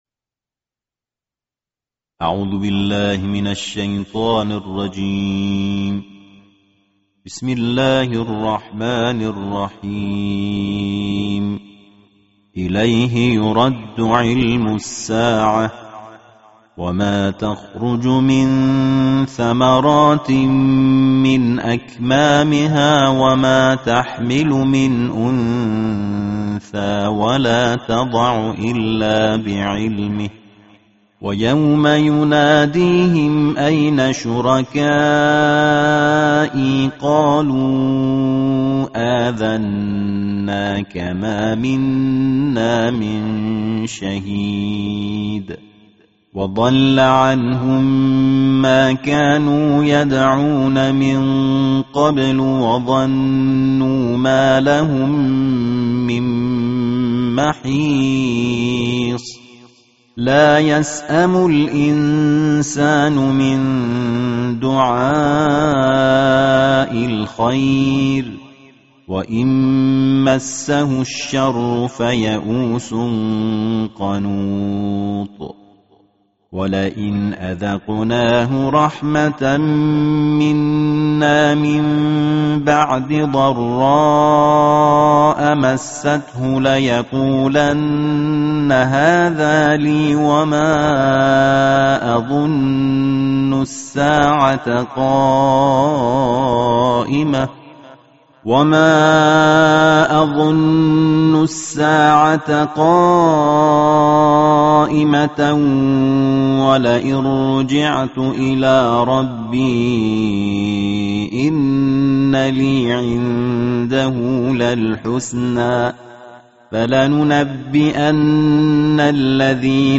Tartil
Qori Internasional